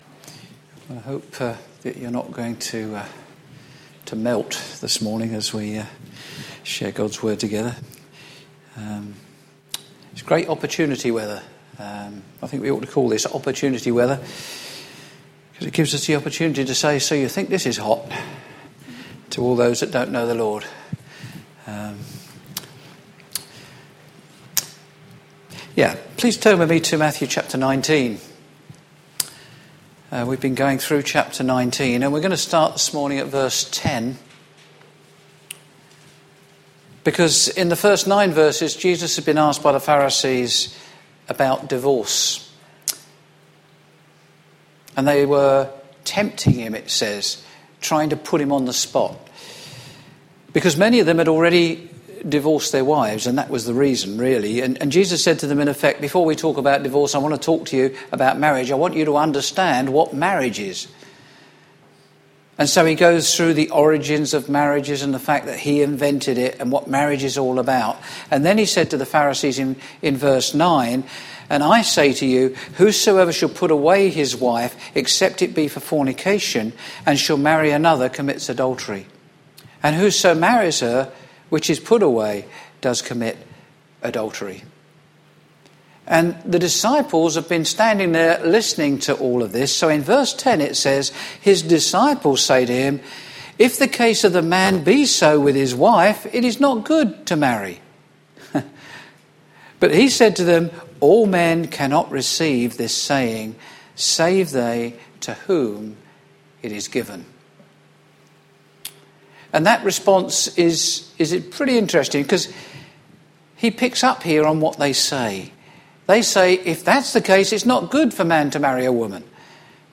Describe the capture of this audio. Share this: Click to share on Twitter (Opens in new window) Click to share on Facebook (Opens in new window) Click to share on WhatsApp (Opens in new window) Series: Sunday morning studies Tagged with Verse by verse